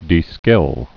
(dē-skĭl)